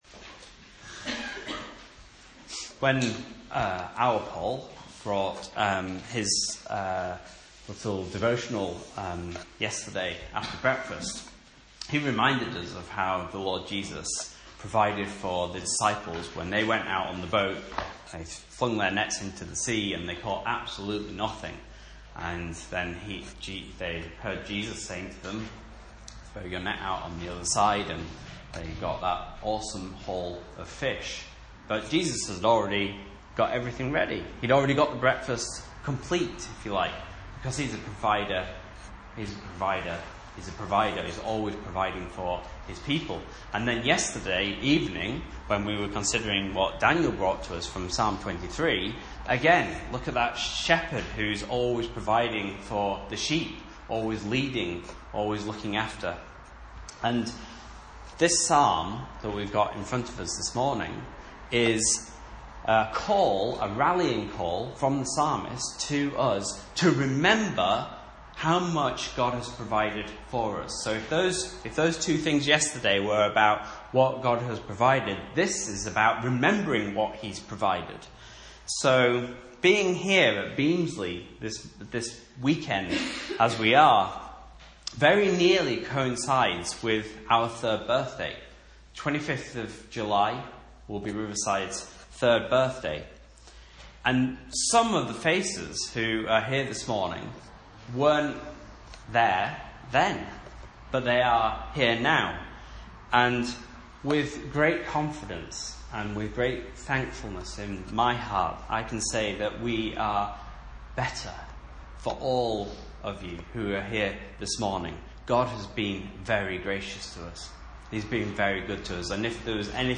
Message Scripture: Psalm 103 | Listen